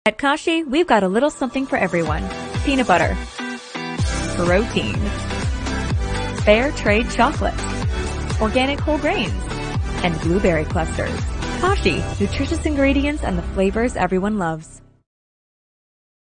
Female
Yng Adult (18-29), Adult (30-50)
Television Spots